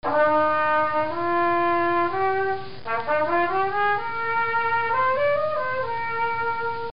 Trumpet
trumpet.mp3